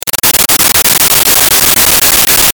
Descobrim a quin interval pertany aquests dos sons realitzats amb dues casseroles de diferent grandària i grossor.
cacerolada_de_intervalos1.mp3